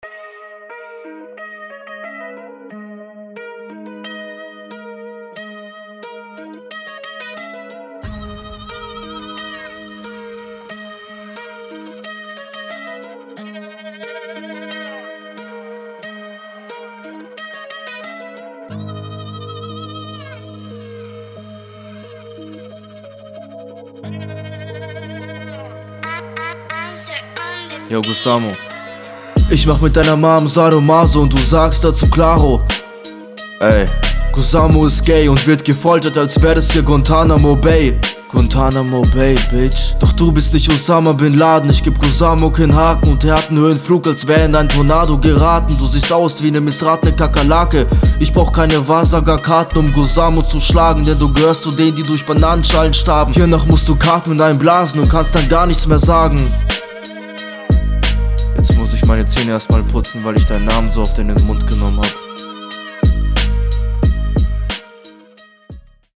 Du musst unbedingt versuchen, besser den Takt zu treffen.